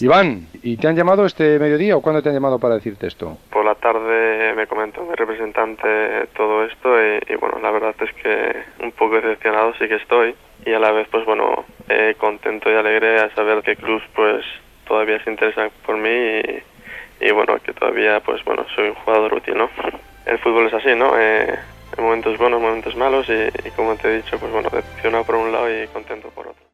Declaració del jugador.
Esportiu